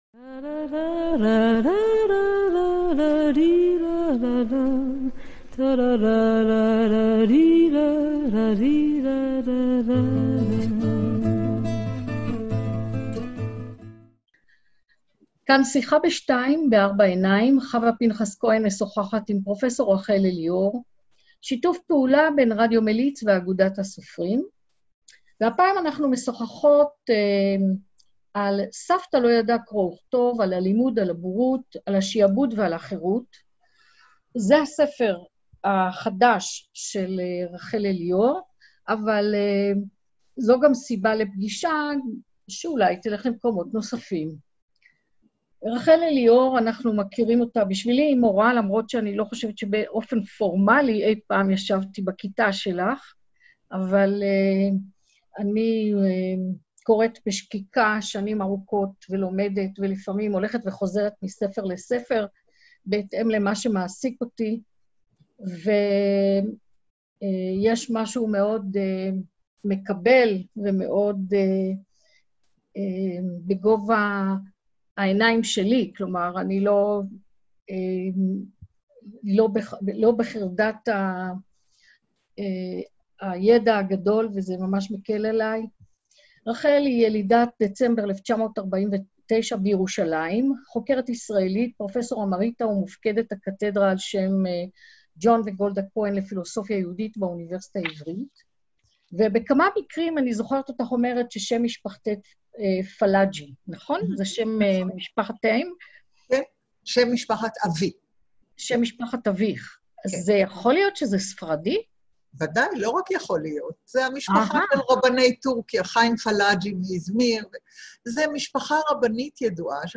שיחה עם רחל אליאור Rachel Elior על ספרה "סבתא לא ידעה קרוא וכתוב" בהוצאת כרמל